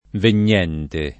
venire v.; vengo [v$jgo], vieni [vL$ni], viene [vL$ne], veniamo [venL#mo], venite [ven&te], vengono [v$jgono] — fut. verrò [